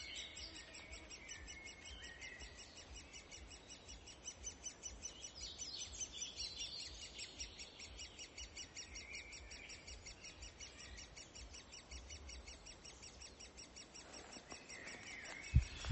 большой пестрый дятел, Dendrocopos major
СтатусМалыши или родители с малышами
ПримечанияČiepst dizraibā dzeņa mazuļi (BirdNet aplikācija noteica)